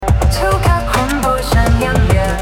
超级恐怖纯音乐